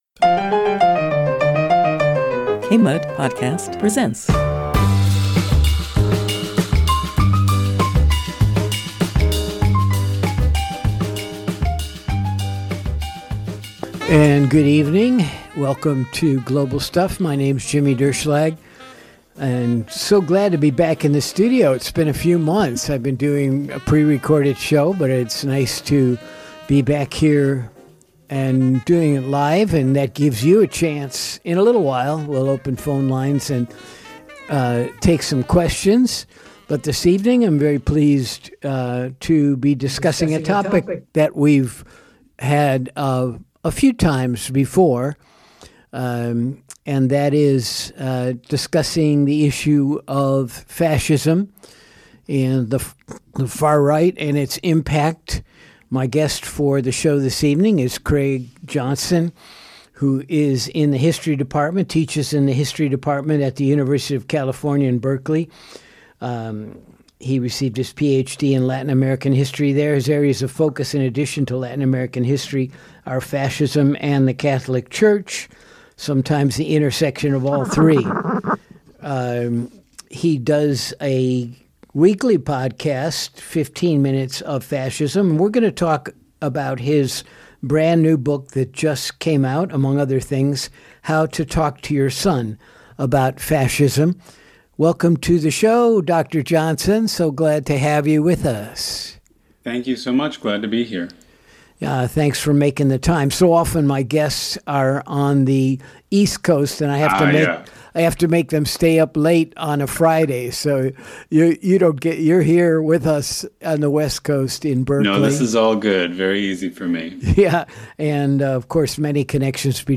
This is a live show with call-ins.